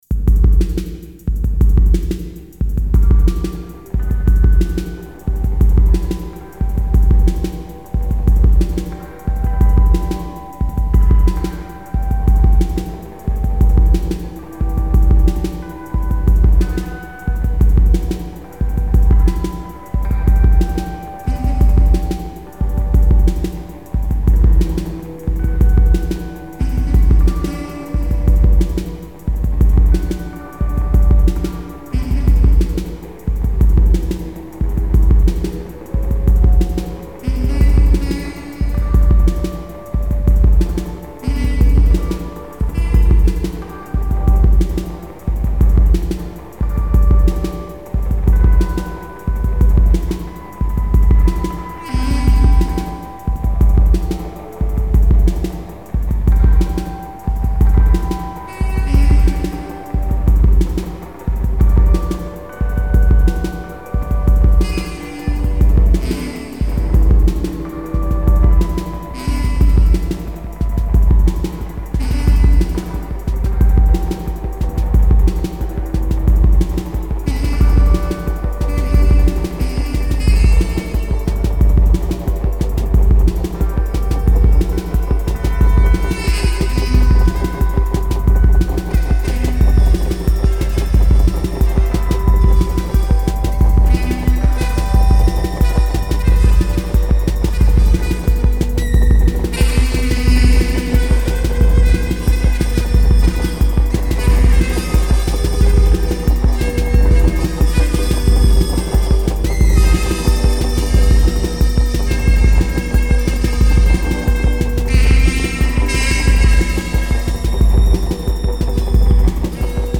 They crop up all over the shortwave spectrum.